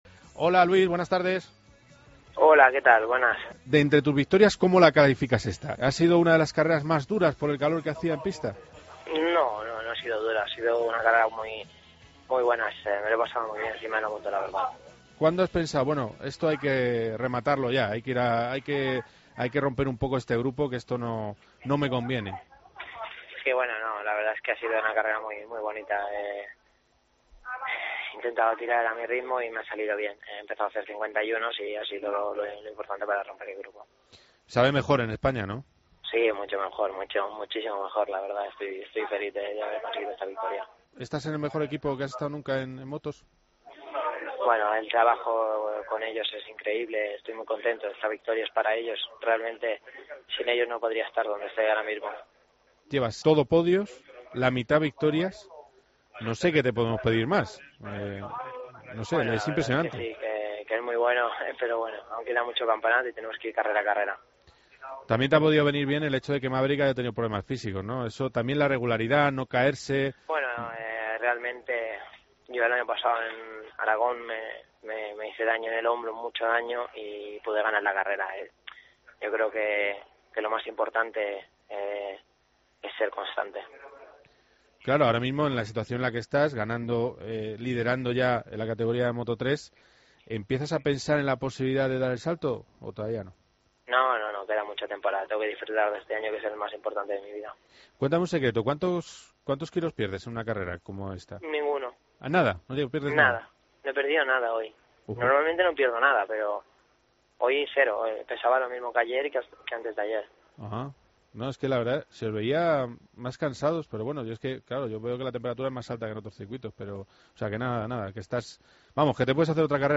Entrevistamos al líder del Mundial de Moto 3, tras su victoria en Montmelo:"Queda mucho campeonato, y hay que ir carrera a carrera".